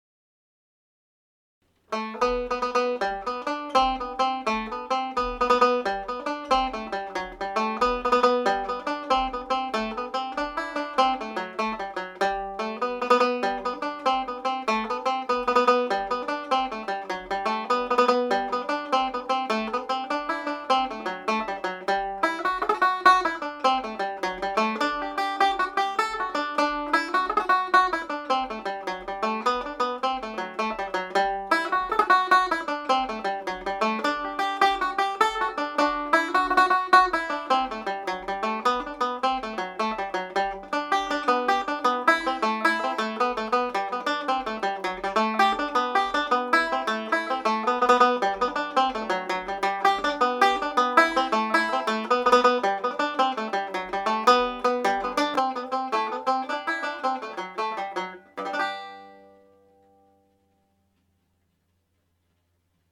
The Humours of Ennistymon played at jig speed
Humours-of-Ennistymon-fast.mp3